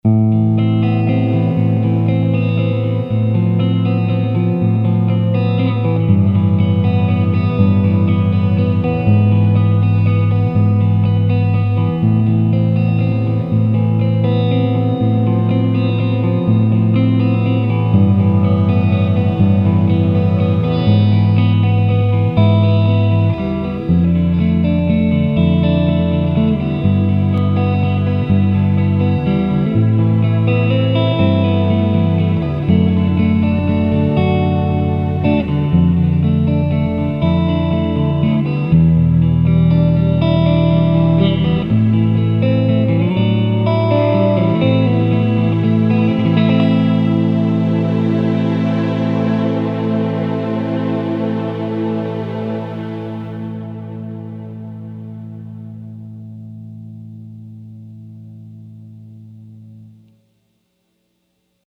In the clip above, I ran the 1/4″ out on the guitar into my pedalboard, and had the MIDI out go into the synth so I could have a nice background string pad to play over.
As you can tell, the natural acoustic tone of the SH575 is gorgeous – it doesn’t sound like an acoustic plugged into an amp. It sounds like a big-body acoustic with a microphone in front of it!